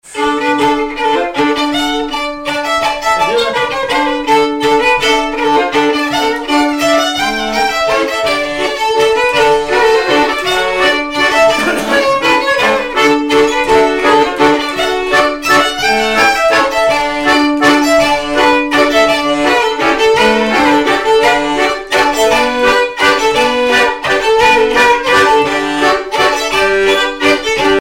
danse : mazurka
violon
Pièce musicale inédite